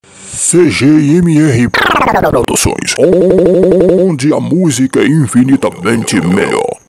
VINHETAS !